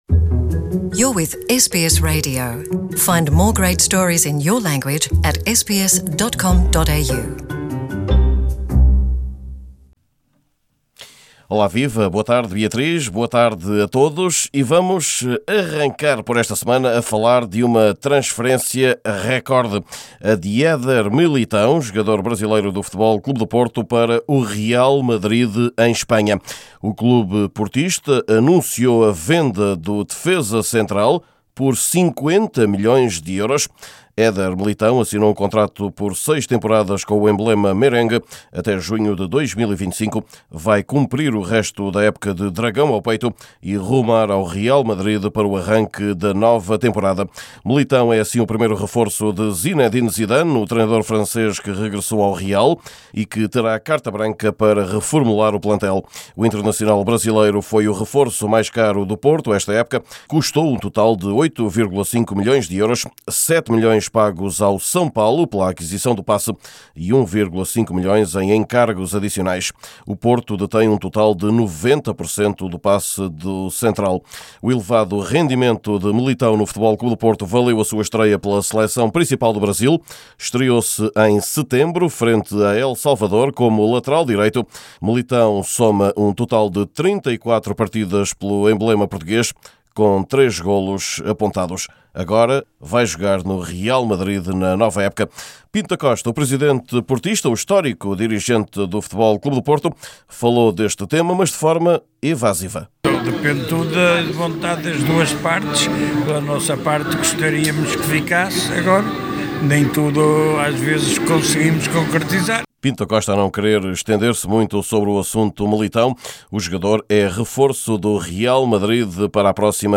Neste boletim semanal, trazemos também a primeira reação do presidente portista, Pinto da Costa.